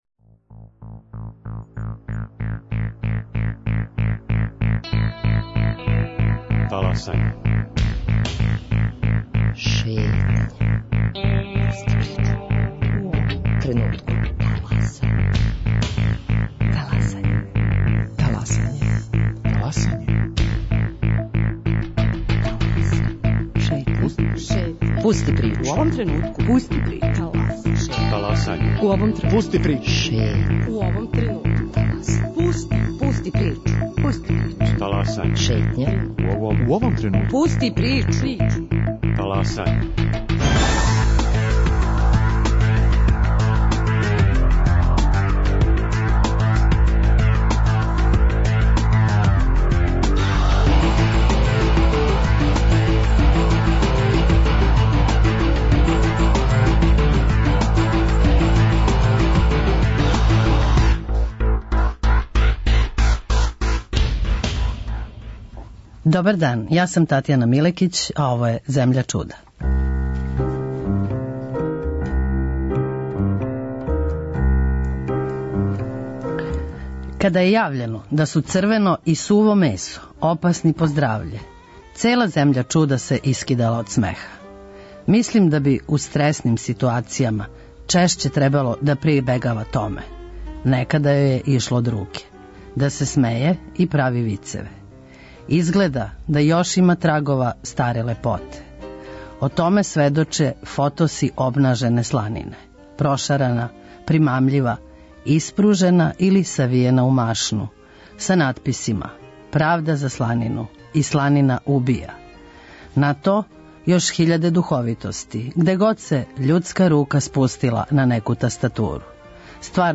Партијска омладина дискутује (и пева):